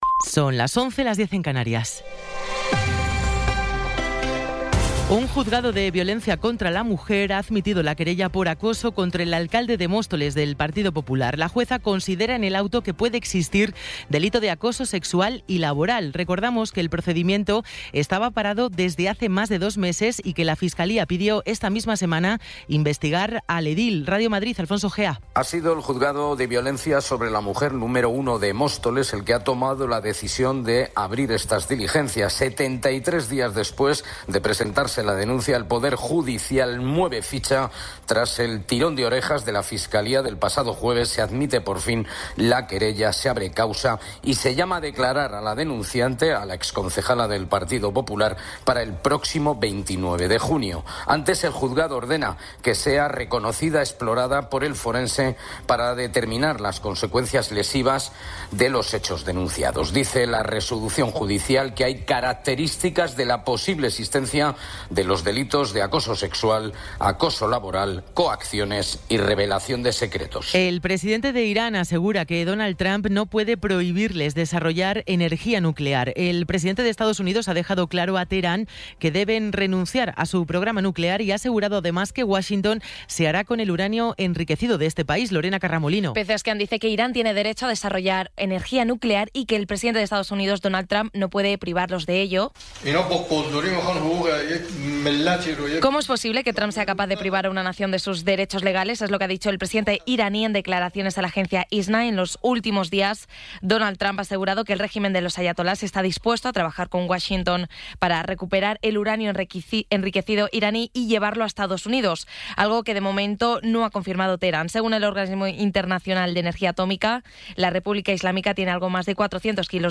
Resumen informativo con las noticias más destacadas del 19 de abril de 2026 a las once de la mañana.